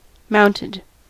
Ääntäminen
Ääntäminen US : IPA : [ˈmaʊn.tɪd] Haettu sana löytyi näillä lähdekielillä: englanti Käännös Adjektiivit 1. ratsastava 2. ratsailla oleva 3. ratsu- Mounted on sanan mount partisiipin perfekti.